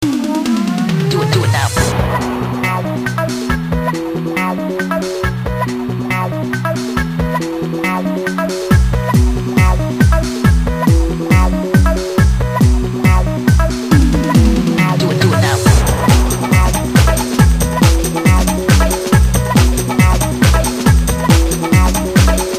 cheesy funky house track